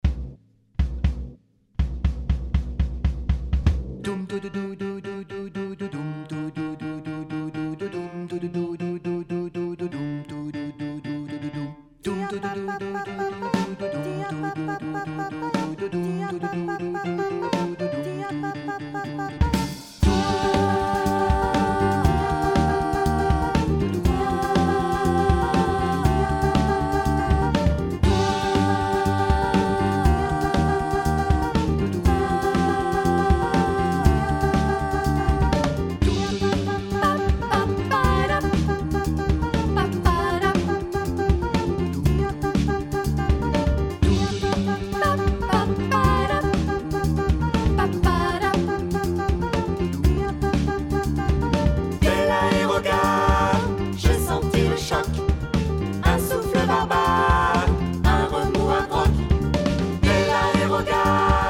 15 tracks arranged for mixed choir and jazz trio